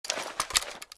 rifle_holster.ogg